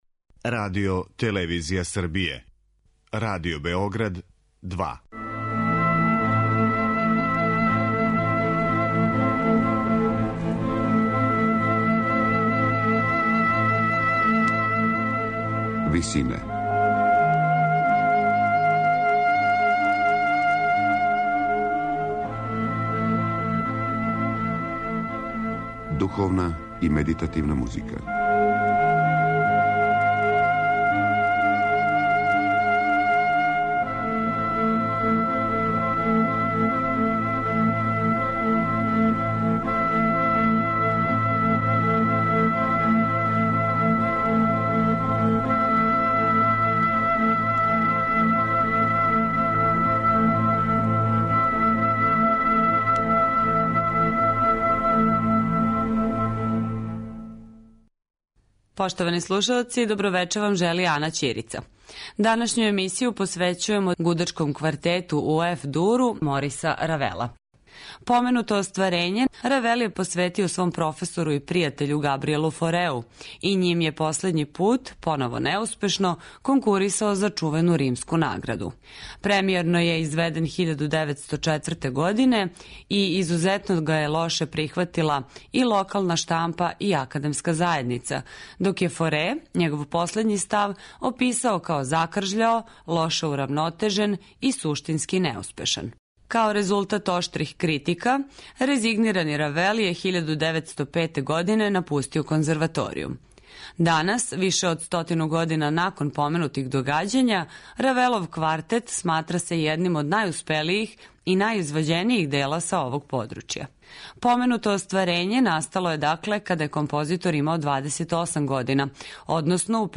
Морис Равел: Гудачки квартет (1903)